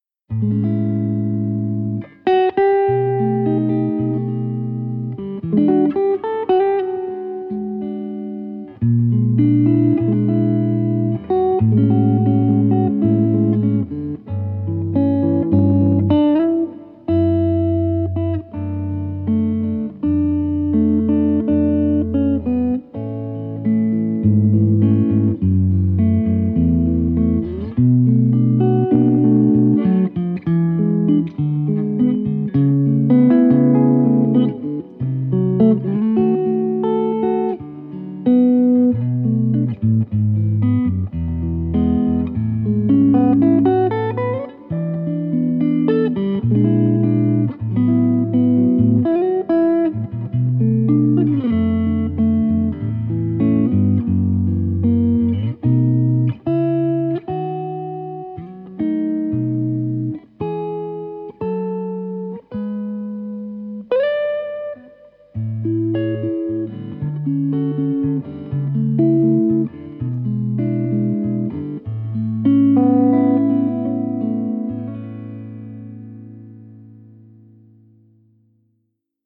Une guitare BOW télécaster Blackguard Butterscotch, avec un beau manche en érable flammé, équipée d’un P90 en grave et d’un micro telecaster aigu, de chez Hepcat Pickups.Parfaite pour la country , en position aigu, et bien pour le jazz en position grave….
P90